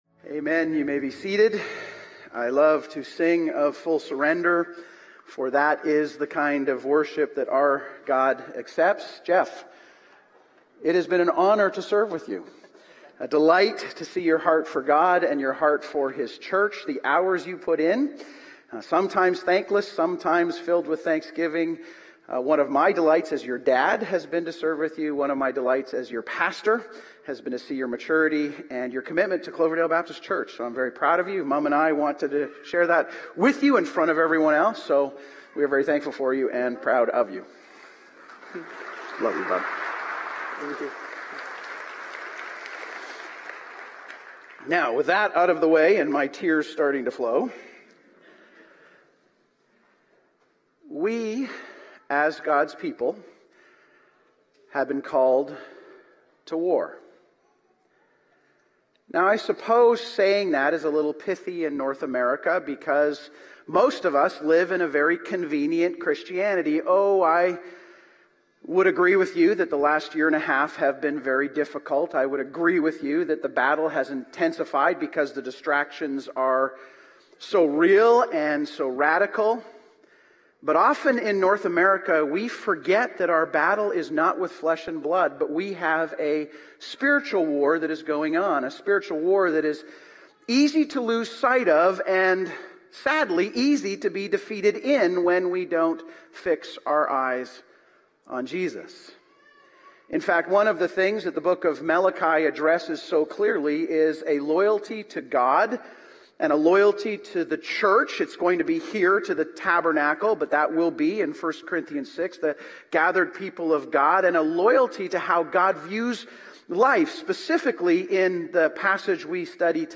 Malachi 2:10-16 Sermon